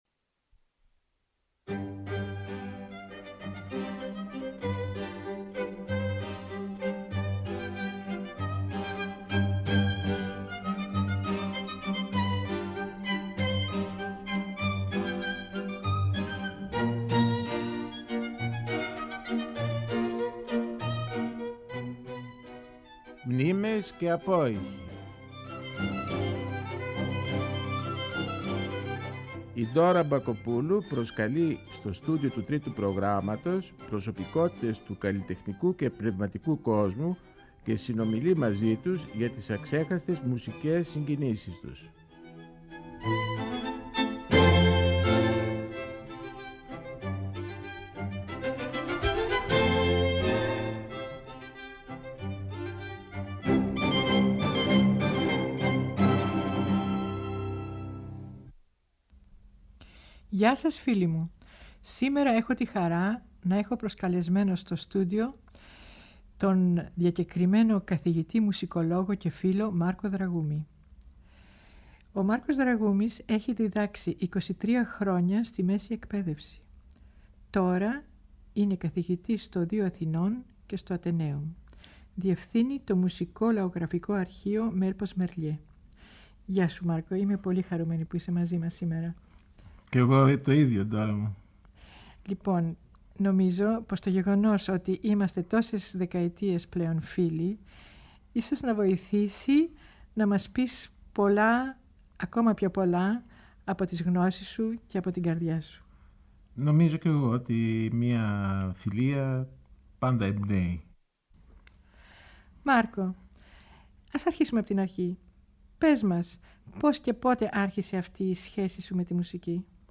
Το Τρίτο Πρόγραμμα τιμά την μνήμη του διακεκριμένου μουσικολόγου Μάρκου Δραγούμη, μεταδίδοντας τα Σάββατα 21 και 28 Ιανουαρίου στις 4 το απόγευμα την συζήτηση που είχε με τη Ντόρα Μπακοπούλου, στο πλαίσιο μιας σειράς συνεντεύξεων που παρουσίαζε η εκλεκτή πιανίστα ,τη δεκαετία του 90 στο Τρίτο, με τίτλο «Μνήμες και απόηχοι» με καλεσμένους προσωπικότητες του πνευματικού και καλλιτεχνικού κόσμου.